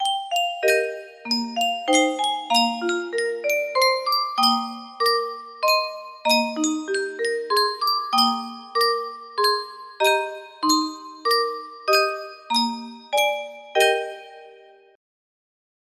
Yunsheng Music Box - The First Noel Y056 music box melody
Full range 60